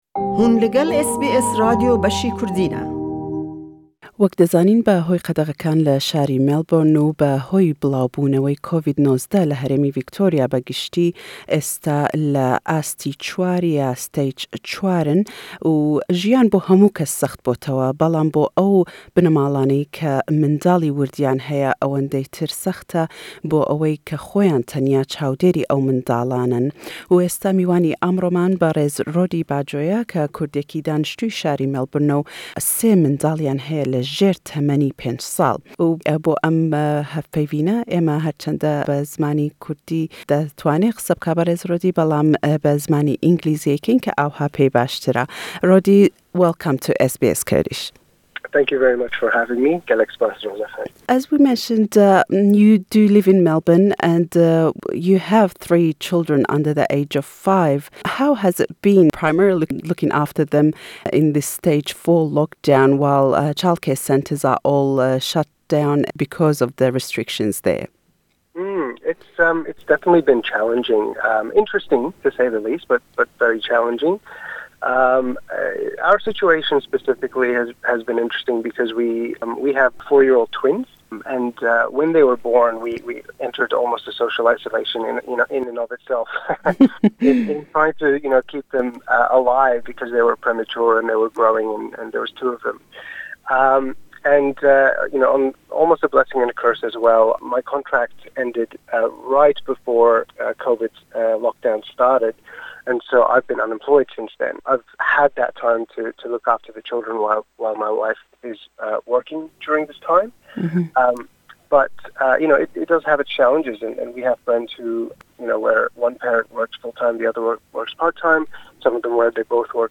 (Kurdish introduction, interview is in English).